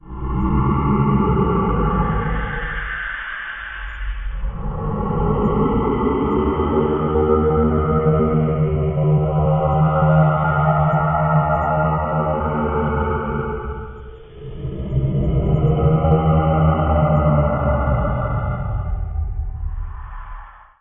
令人毛骨悚然的恐怖 " 立体单片机
描述：我处理了。
标签： 呻吟 怪物
声道立体声